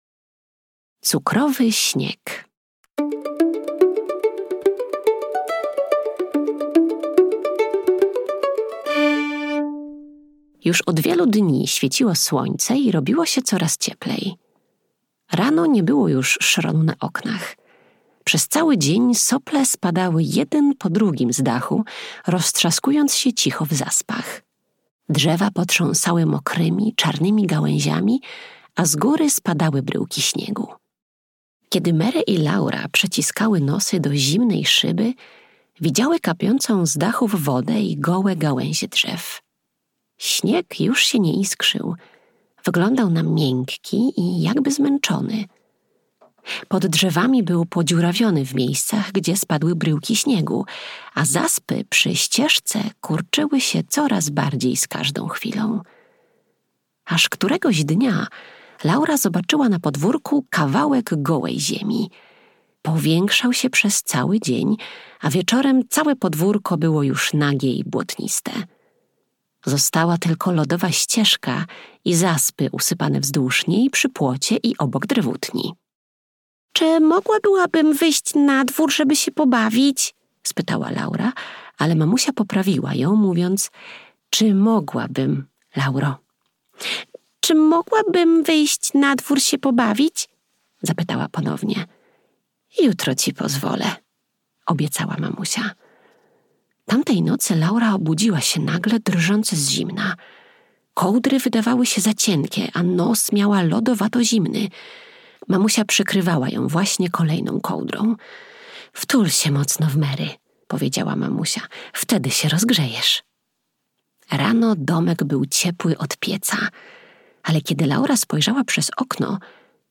Mały domek w Wielkich Lasach - Laura Ingalls Wilder - audiobook